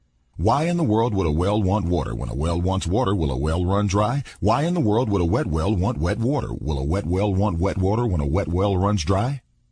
tongue_twister_04_02.mp3